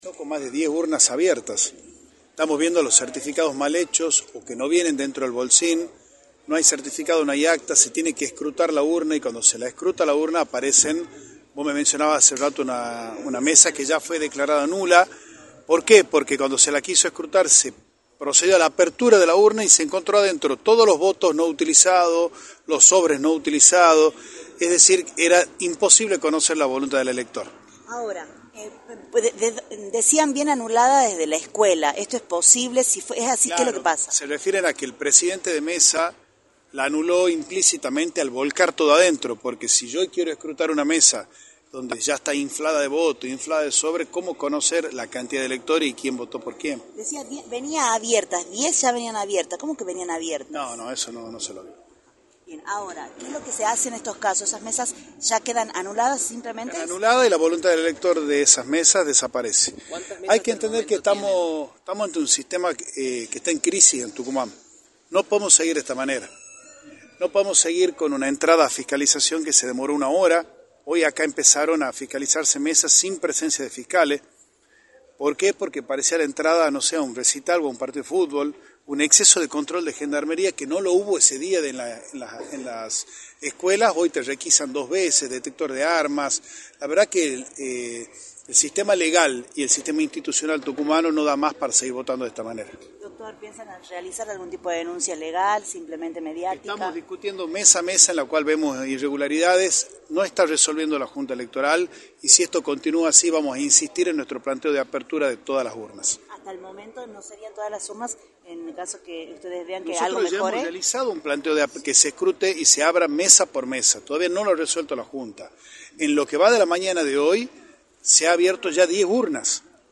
en entrevista para Radio del Plata, por la 93.9.